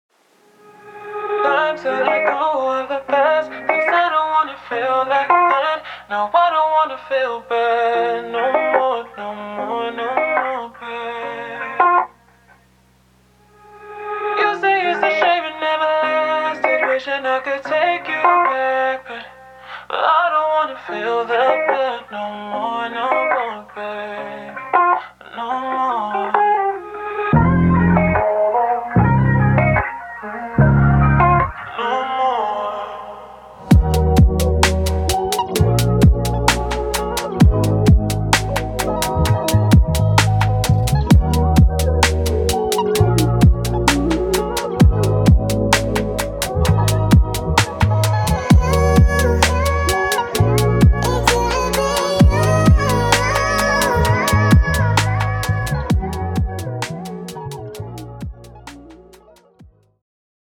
guitar
Recorded and mixed in the UK, US, & Spain.